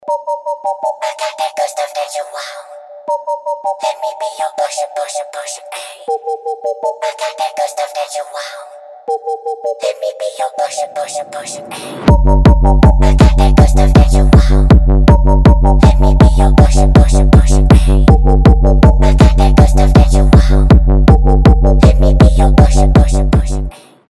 ритмичные
Electronic
электронная музыка
динамичные
techno
hard techno
Техно